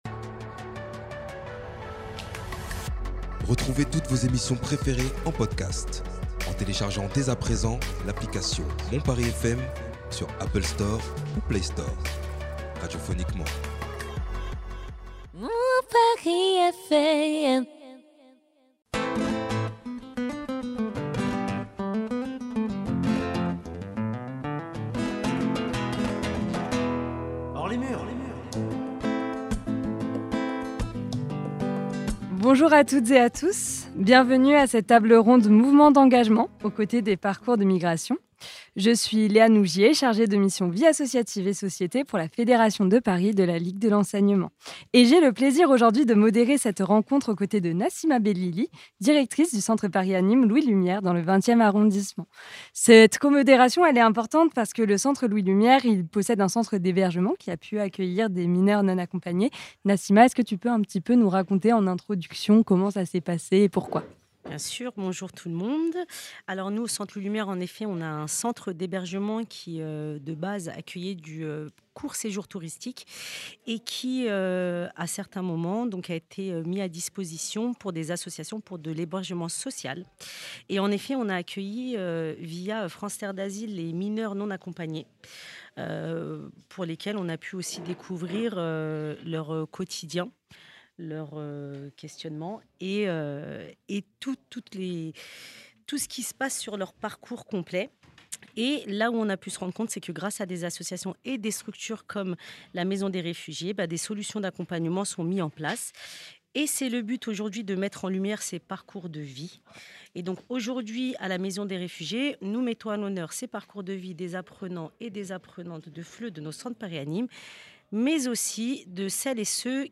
Cette table ronde enregistrée à la Maison des Réfugiés réunit 5 profils engagés qui soutiennent et accompagnent les personnes en situation de migration.